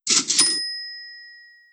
Buy Object Sound.wav